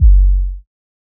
Short South 808.wav